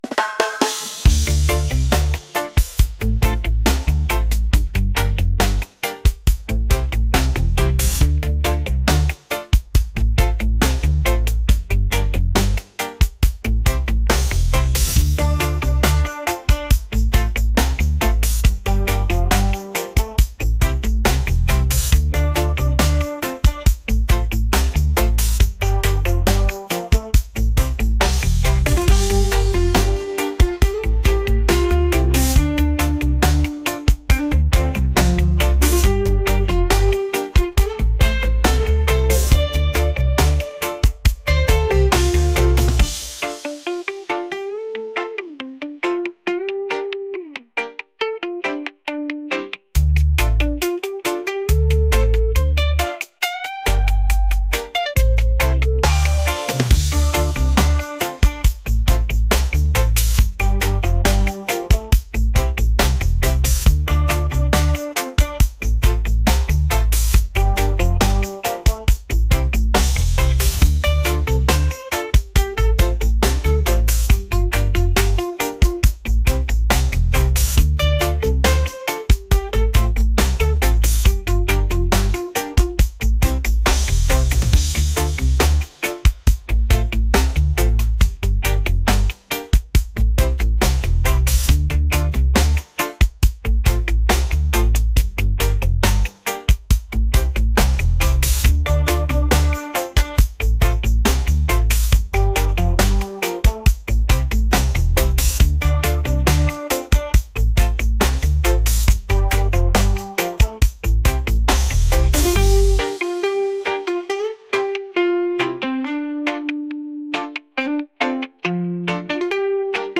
reggae | funk | world